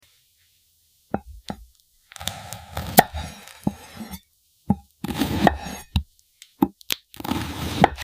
ASMR Cutting Real Cucumber 🥒🔪✨ sound effects free download
The crunch is just perfect… so fresh and satisfying!